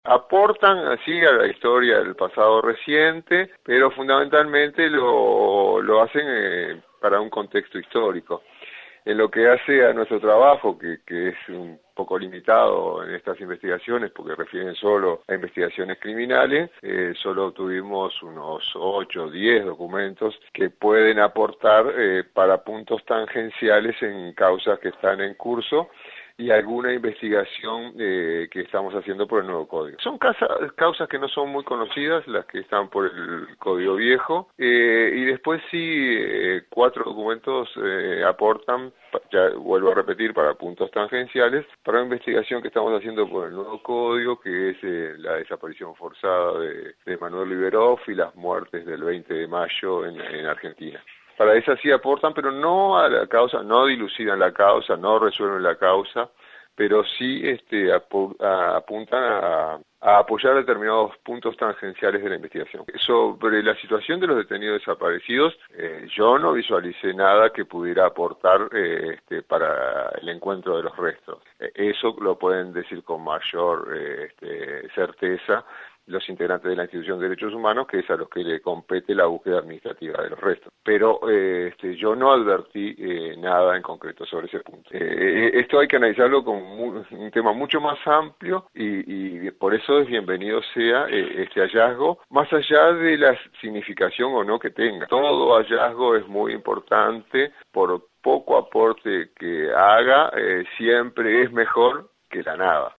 En diálogo con 970 Noticias, el fiscal de Delitos de Lesa Humanidad, Ricardo Perciballe se refirió al hallazgo de documentación referida al período de la dictadura en la unidad de Grupo de Artillería número 5 de Montevideo.